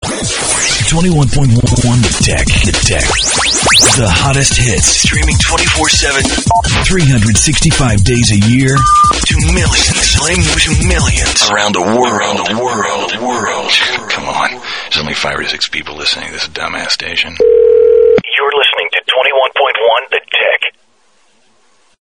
RADIO IMAGING / HOT AC